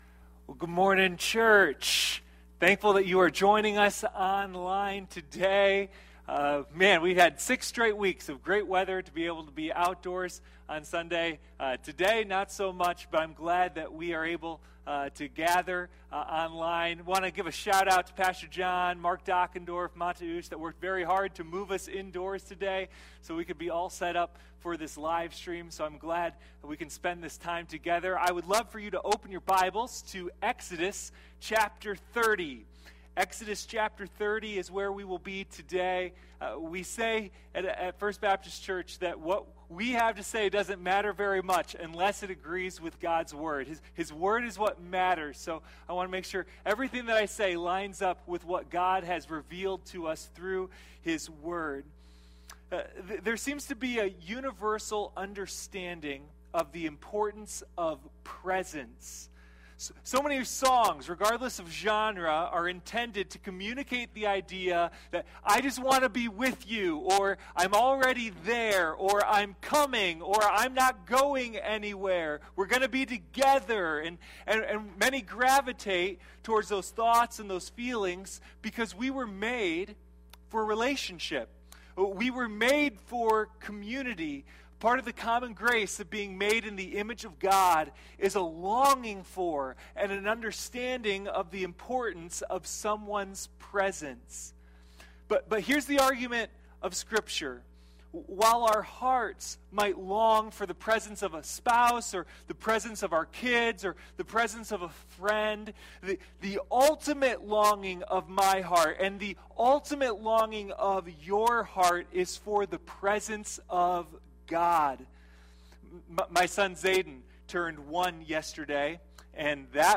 Sunday Morning Communion: A Study in Exodus